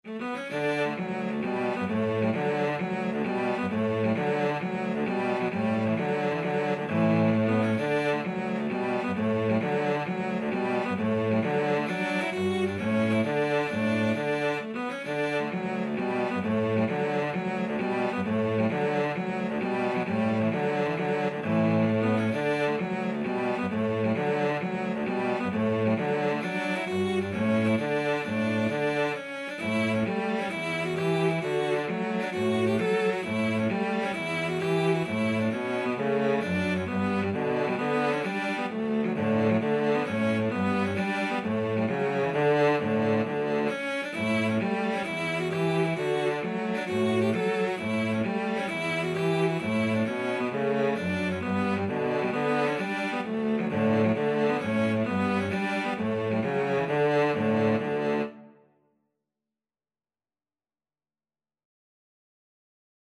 Free Sheet music for Cello Duet
D major (Sounding Pitch) (View more D major Music for Cello Duet )
=132 Molto allegro
Traditional (View more Traditional Cello Duet Music)